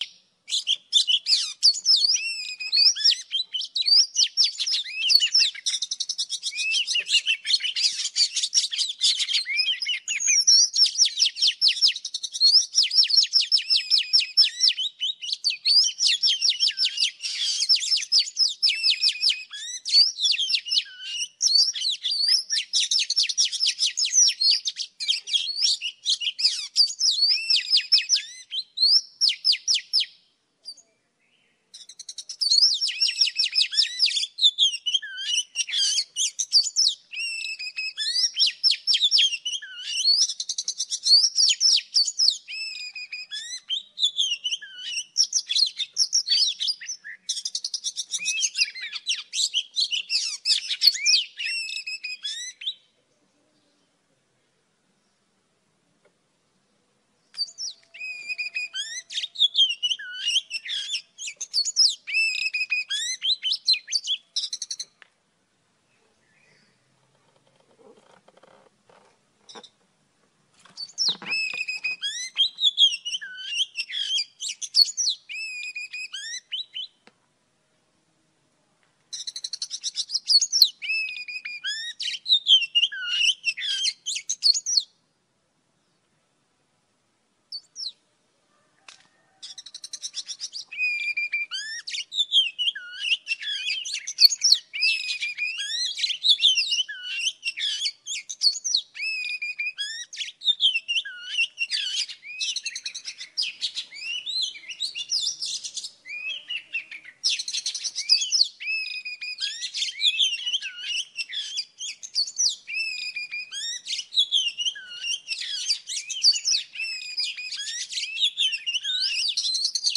Tiếng Chích Chòe Than mái hót
Tiếng động vật 275 lượt xem 23/02/2026
Khác với giọng hót lắt léo và đầy uy lực của chim trống, Chích Chòe Than mái sở hữu giọng hót nhẹ nhàng, đều đặn với những âm tiết "chòe... chòe..." đặc trưng.
• Tạo nhịp điệu tự nhiên: Với những quãng nghỉ đều đặn, tiếng mái hót giúp video có nhịp điệu hài hòa, không quá dồn dập nhưng vẫn đủ sinh động để thu hút người xem.
• Bản thu sạch tạp âm: File âm thanh được lọc bỏ các tiếng nhiễu môi trường, đảm bảo giọng hót của chim mái luôn trong trẻo và chân thực nhất khi lồng ghép vào dự án.